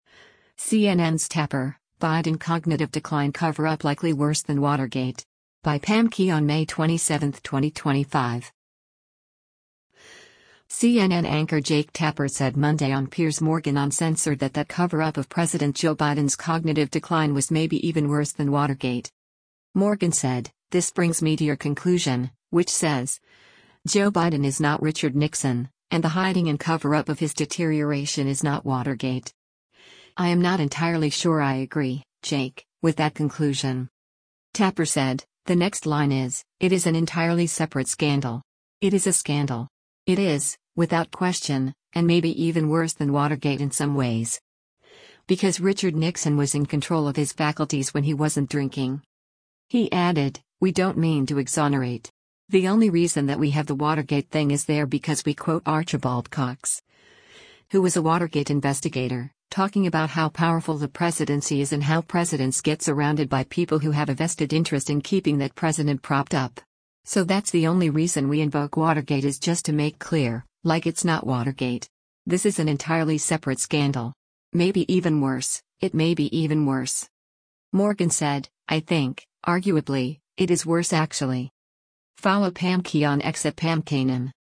CNN anchor Jake Tapper said Monday on “Piers Morgan Uncensored” that that cover-up of President Joe Biden’s cognitive decline was “maybe even worse than Watergate.”